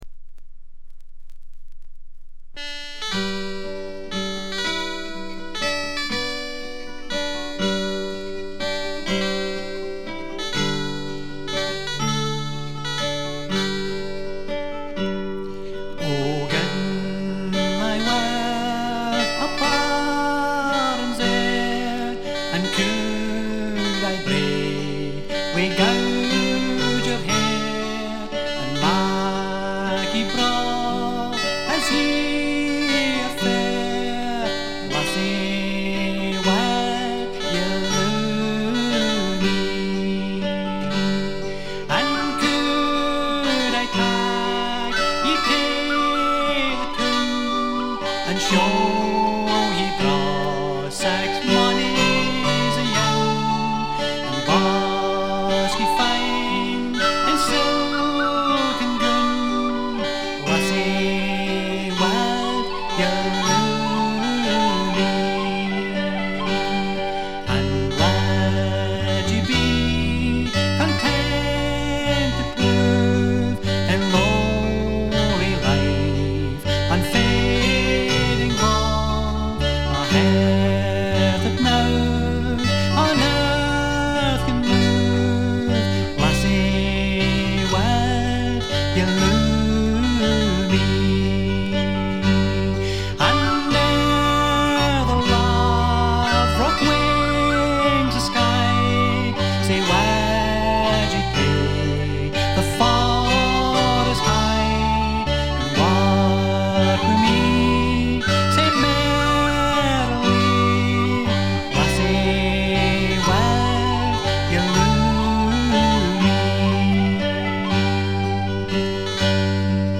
わずかなチリプチ程度。
スコットランドのトラッド・グループ
ギター、笛、アコーディオン、パイプ等が織りなす美しい桃源郷のような世界が展開されます。
試聴曲は現品からの取り込み音源です。
vocals, mandola, mandoline, whistle, guitar
vocals, banjo, guitar
accordion, Highland pipes, keyboards